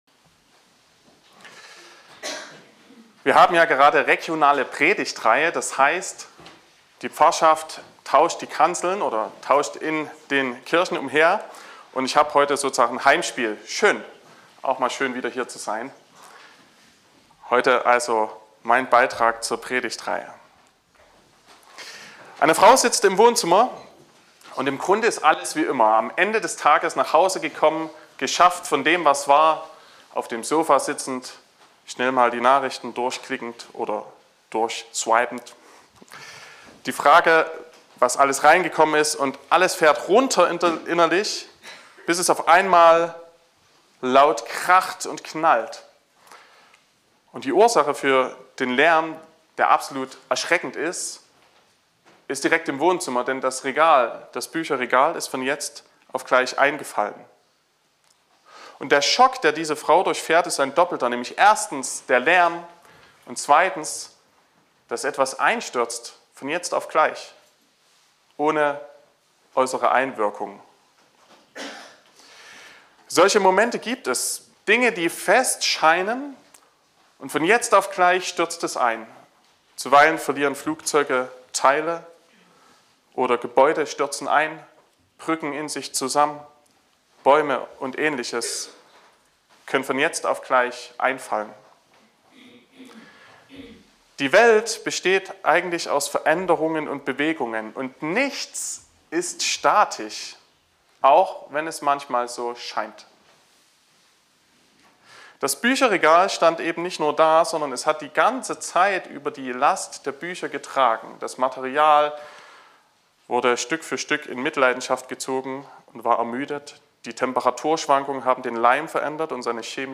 21.01.2024 – Gottesdienst
Predigt (Audio): 2024-01-21_Der_Kipppunkt_zum_Guten__Predigtreihe_2024__Thema_4_.mp3 (25,5 MB)